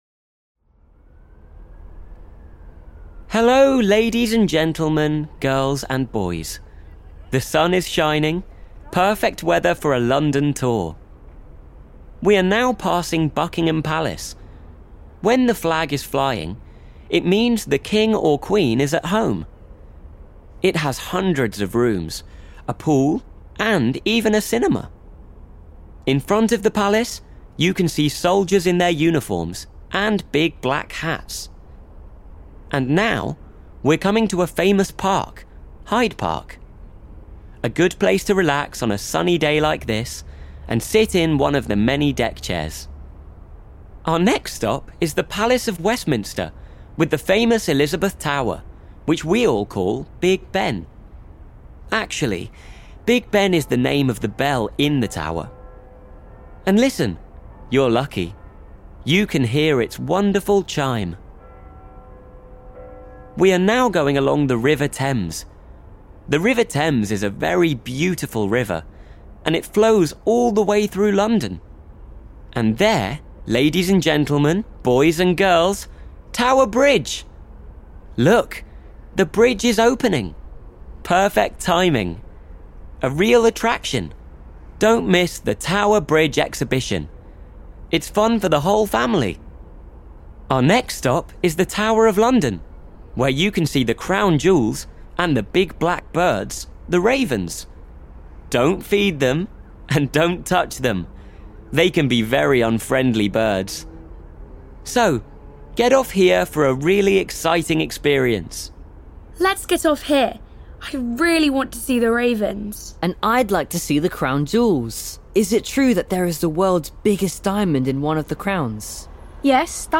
Wir machen mit unseren Freunden Emma, Ben und Amy eine Busrundfahrt und hören zu, was uns der Tourguide erzählt.